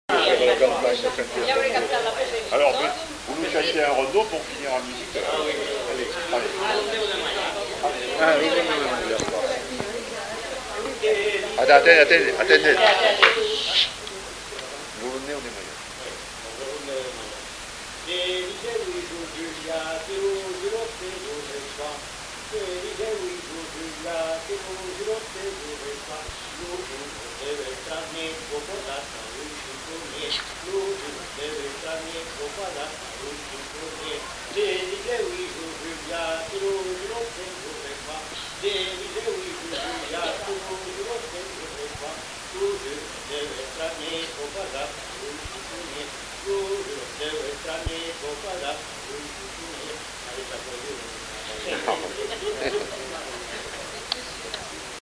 Lieu : Bazas
Genre : forme brève
Effectif : 1
Type de voix : voix d'homme
Production du son : chanté
Danse : rondeau